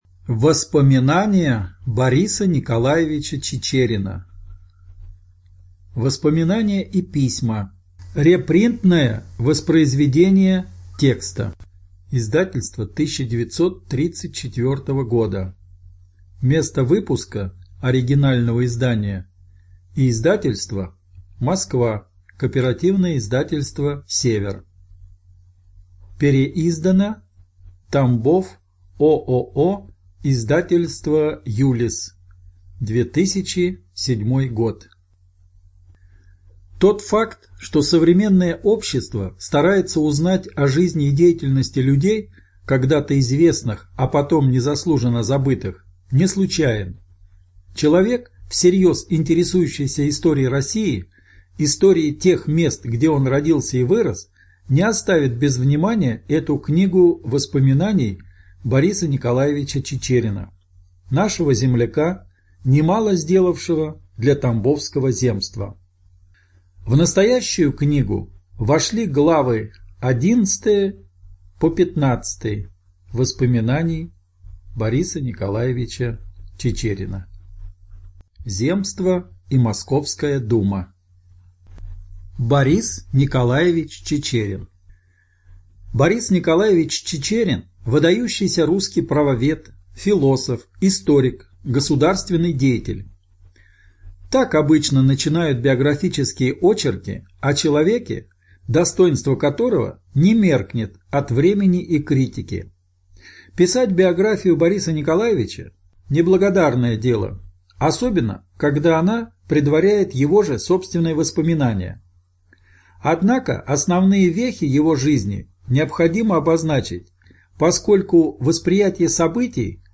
Студия звукозаписиТамбовская областная библиотека имени А.С. Пушкина